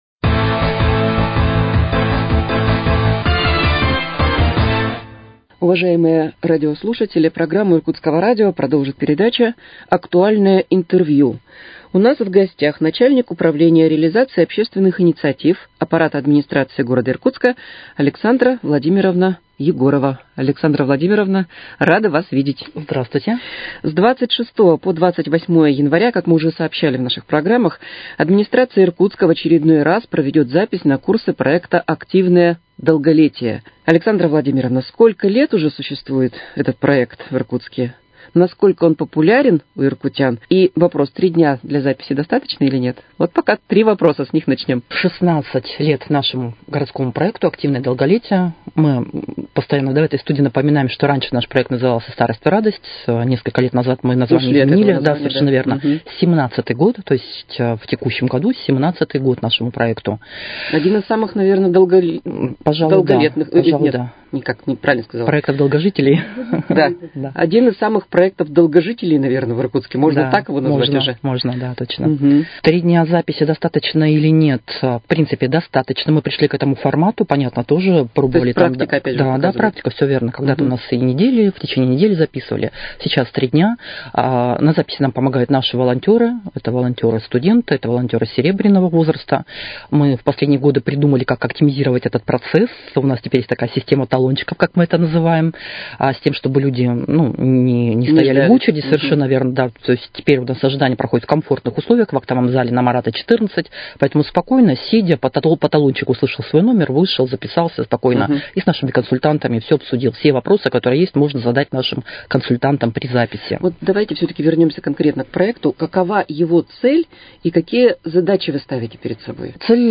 С 26 по 28 января администрация Иркутска в очередной раз проведет запись на курсы проекта «Активное долголетие». В студии Иркутского радио